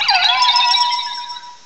cry_not_uxie.aif